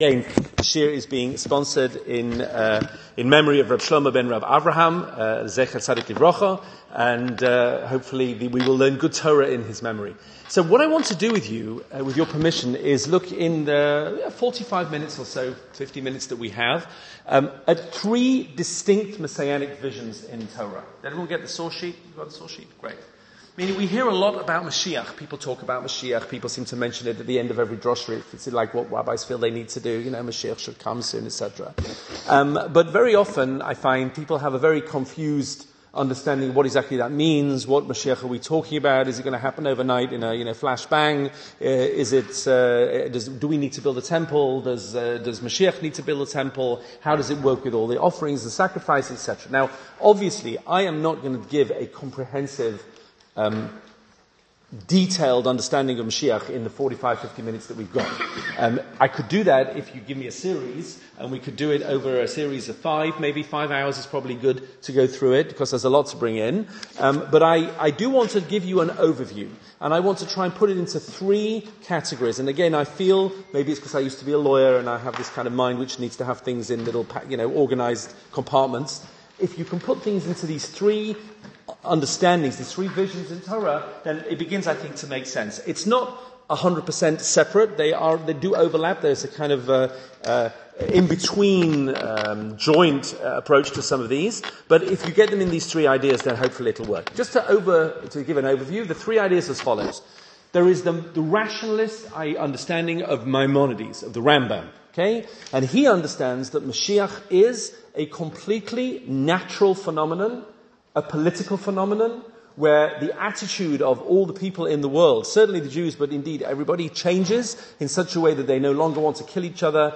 Three Messianic Visions in the Torah - a shiur given in Borehamwood for the Mizrachi Weekend of Inspiration in May 2025